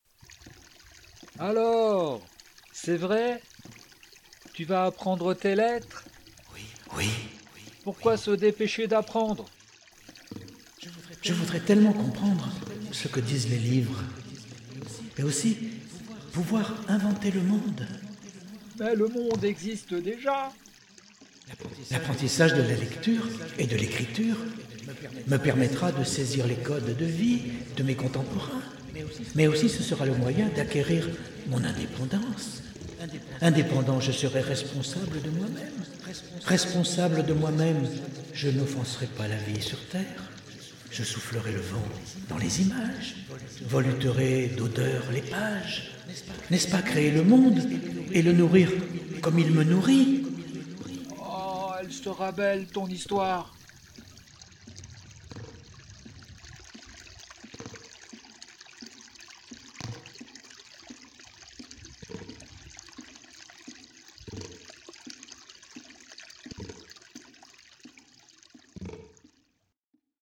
UN MONDE BRUISSANT -essais sonores-
Avec les sons j'ai joué, j'insiste sur ce terme, joué comme un bambin sur les touches d'un piano enfantin : une exploration de mon sens auditif ; une expérimentation des outils numériques -simples- glaner par-ci par-là ; des juxtapositions de bruits enregistrés autour de nous.
avec la complicité vocale